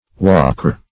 Walkyr \Wal"kyr\, n. (Scand. Myth.)